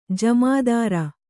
♪ jamādārq